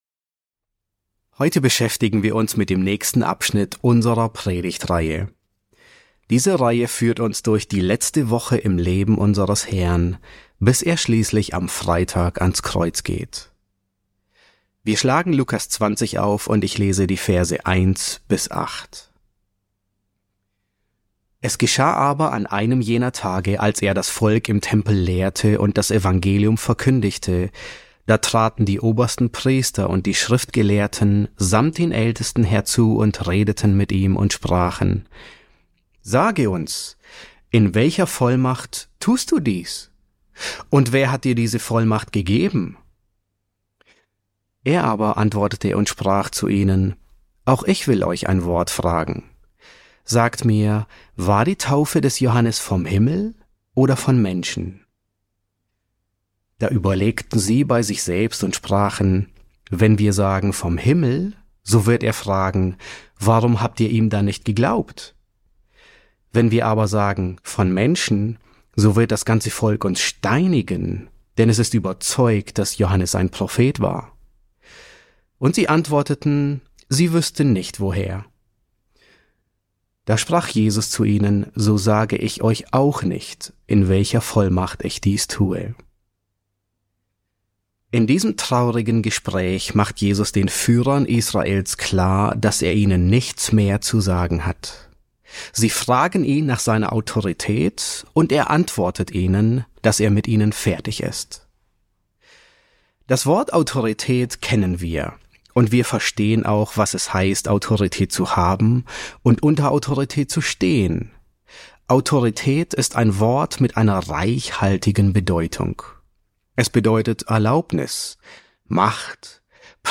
E2 S7 | Die Autorität des Königs wird abgelehnt ~ John MacArthur Predigten auf Deutsch Podcast